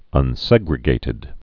(ŭn-sĕgrĭ-gātĭd)